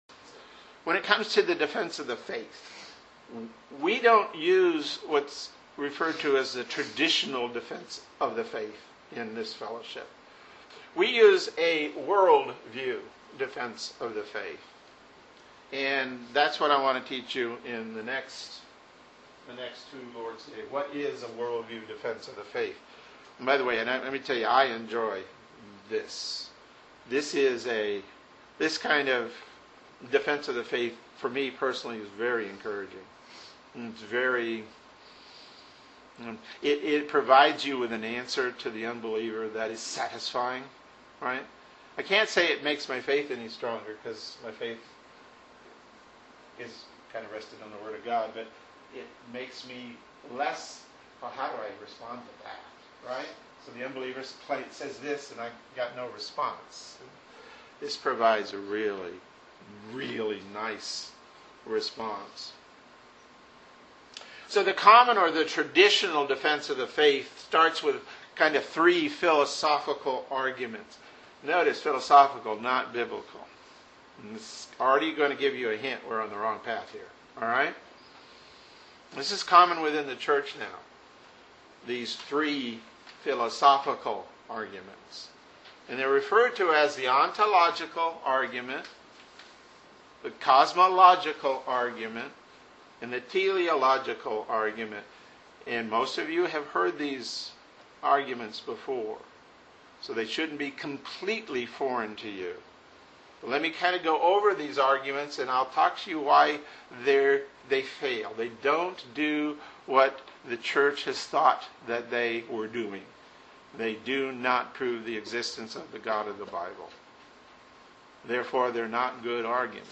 Sermons | Reformed Presbyterian Church of Ocala